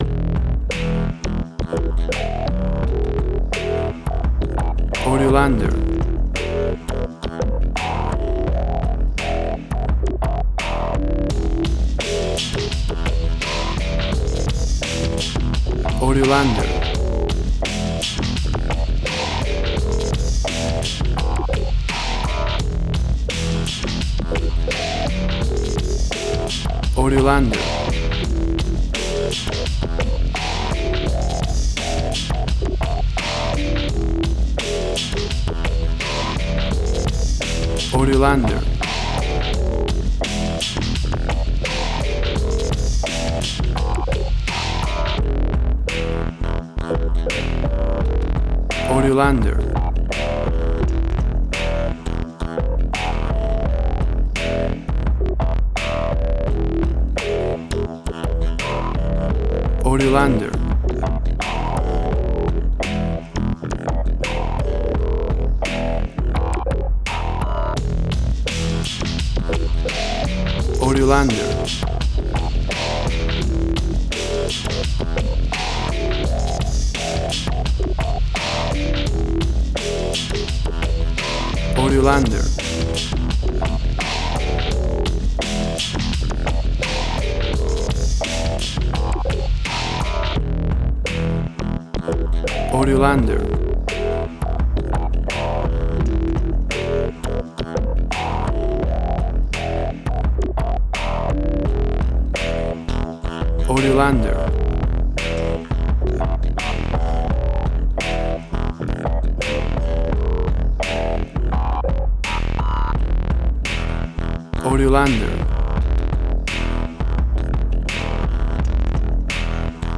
Weird hip hop played by some robots
Tempo (BPM): 85